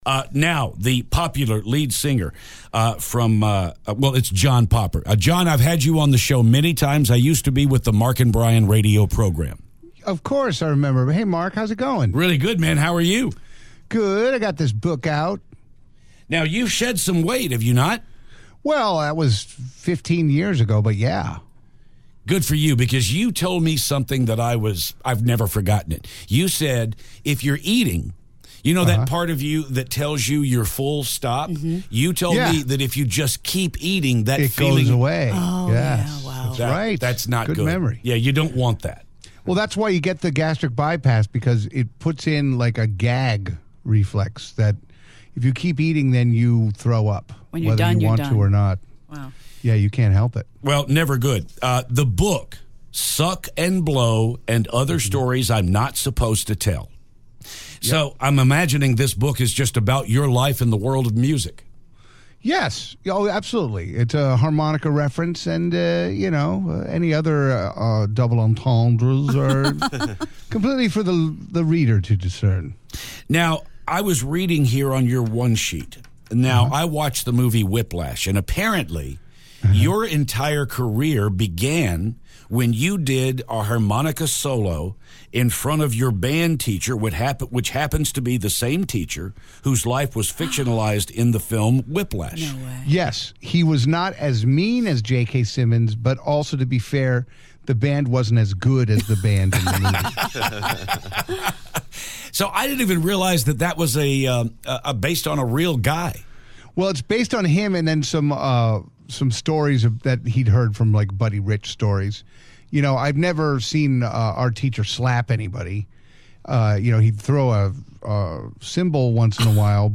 John Popper calls to talk about his new book!